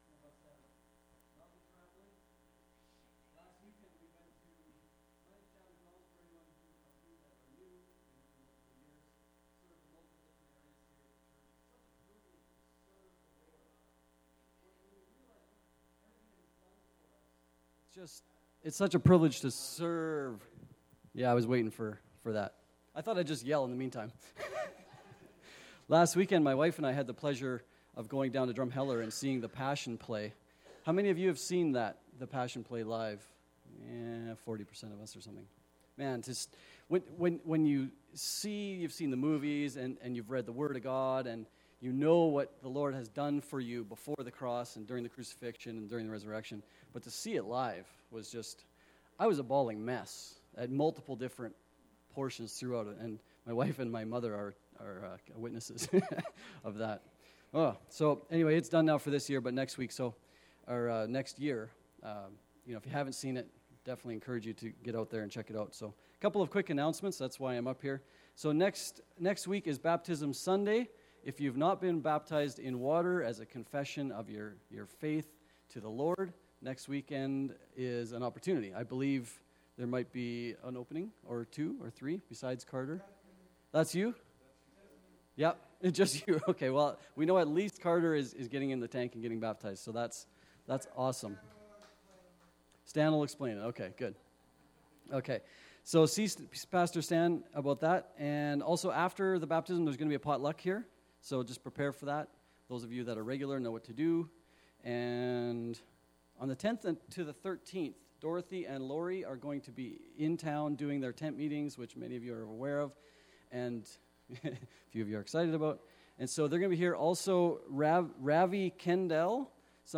July 30 Service | Potters Hands Ministries